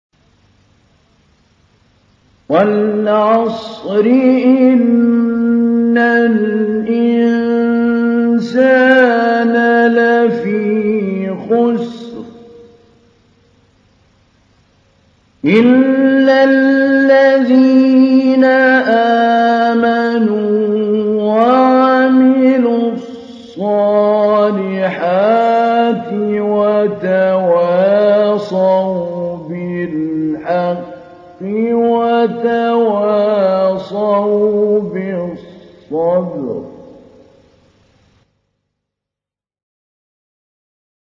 تحميل : 103. سورة العصر / القارئ محمود علي البنا / القرآن الكريم / موقع يا حسين